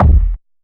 DrKick11.wav